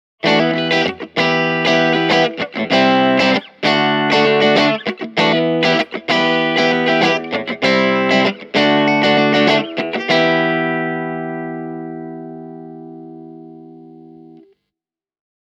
Puhtaissa soundeissa Marshall JTM1C soi avoimella ja kirkkaamalla äänellä, kun taas JMP1C:n ääni on keskialuevoittoisempi ja lämpimämpi.
Marshall JTM1C – Telecaster/puhdas